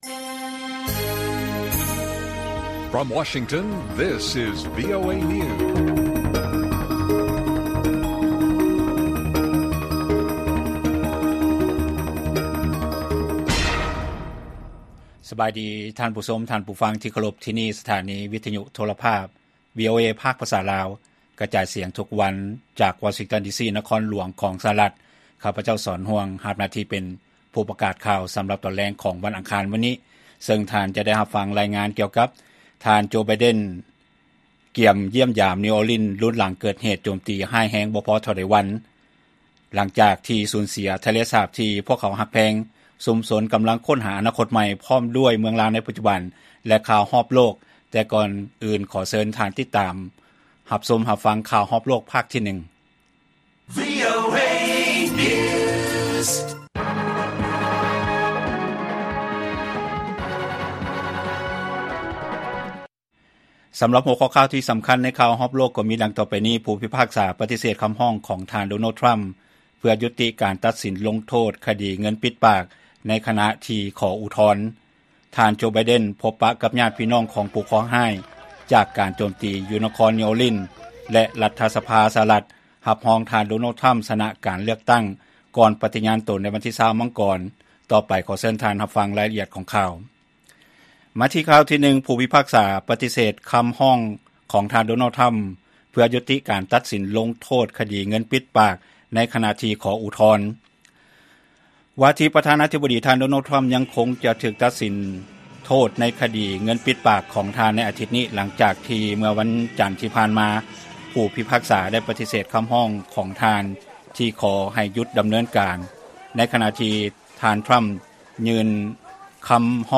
ລາຍການກະຈາຍສຽງຂອງວີໂອເອລາວ: ຜູ້ພິພາກສາປະຕິເສດຄຳຮ້ອງ ທ່ານ ດໍໂນລ ທຣໍາ ເພື່ອຢຸດຕິການຕັດສີນລົງໂທດຄະດີເງິນປິດປາກ ໃນຂະນະທີ່ຂໍອຸທອນ